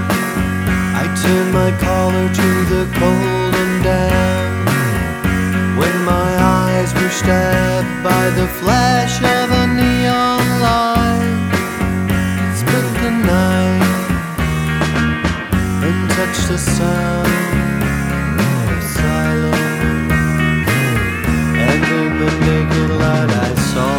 With Harmony Pop (1960s) 3:06 Buy £1.50